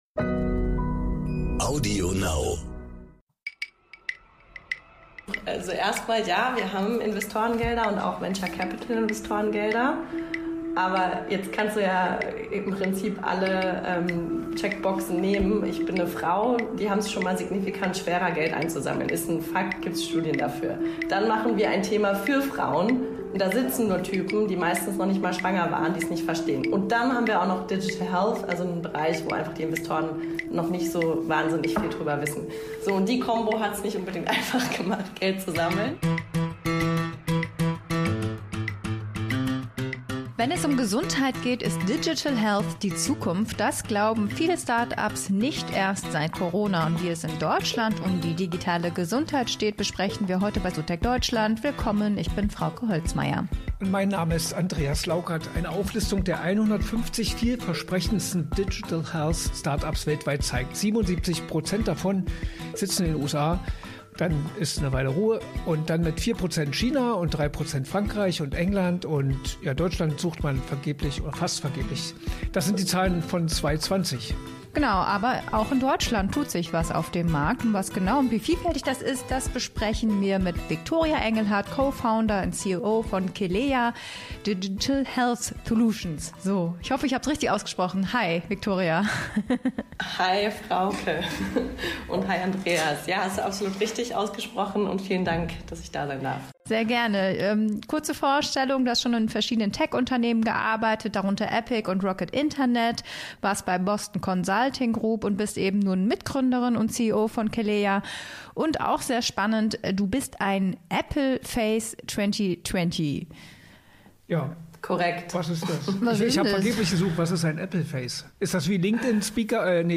"Ich war zu ehrlich", antwortet sie lachend im ntv-Podcast "So techt Deutschland" auf die Frage, was ihr größter Fehler war, als sie bei Investoren Geld einsammeln wollte.